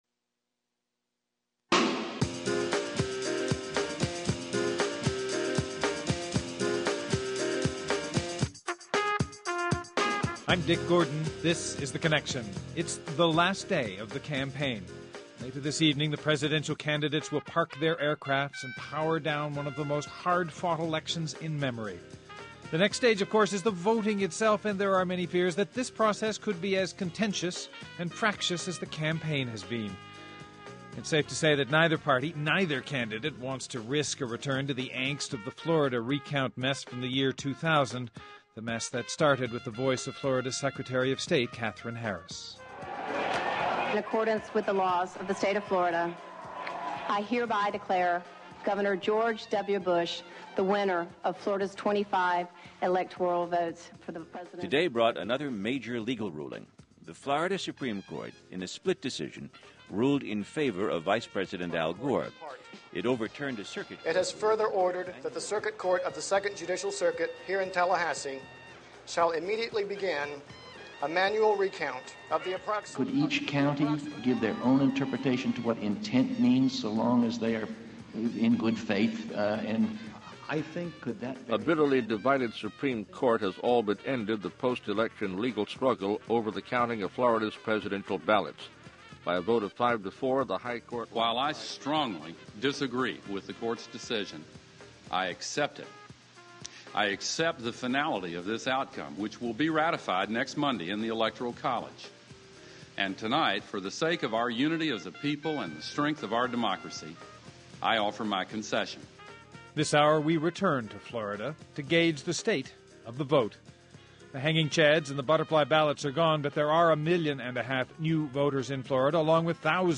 Congresswoman Corinne Brown (D-FL) Ion Sancho, Supervisor of Elections in Leon County, FL Ryan Lizza, Senior Editor, The New Republic